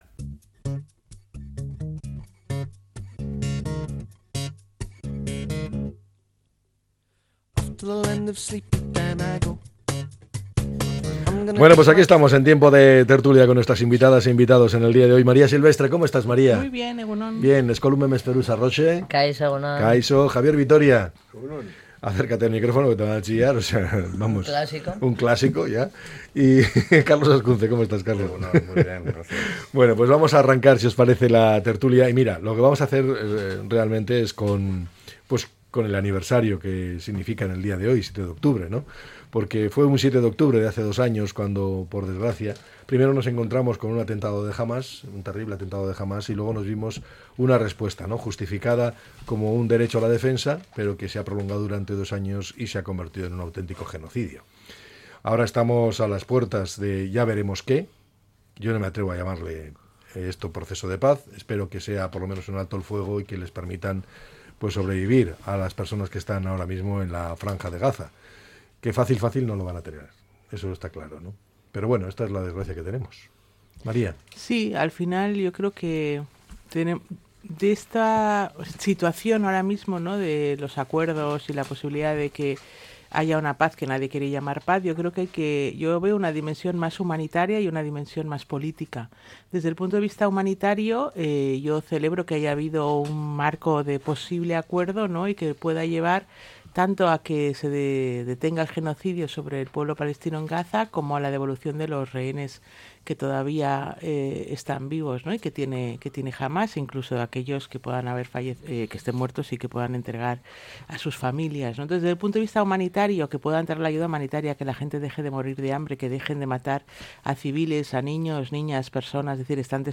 La tertulia 07-10-25.